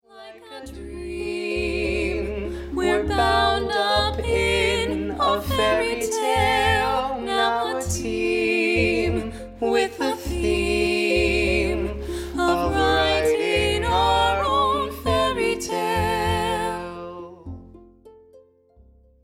Here are some rough demos of a few of the songs: